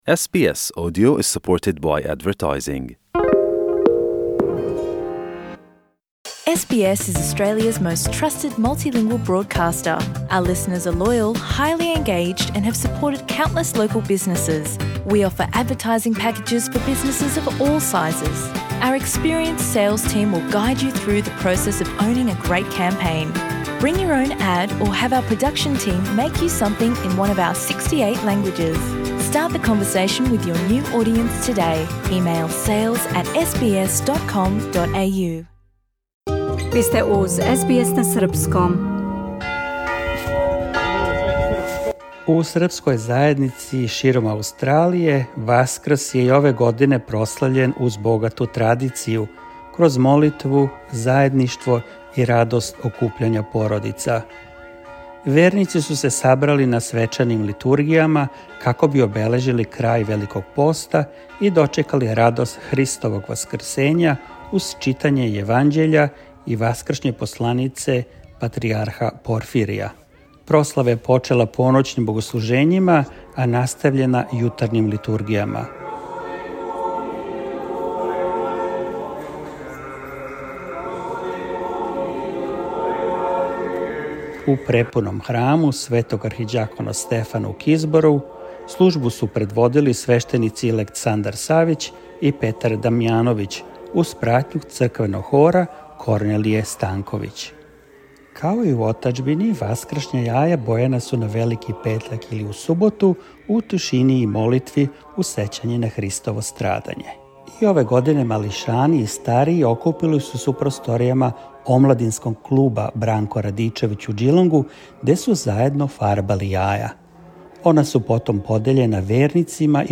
For more stories, interviews, and news from SBS SERBIAN, explore our podcast collection here.